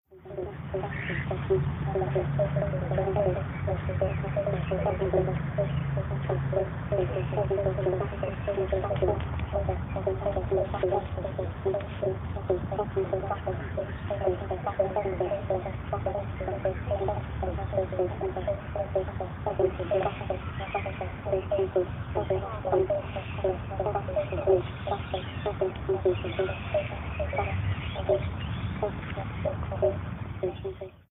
Frogs in Adelaide Botanic Gardens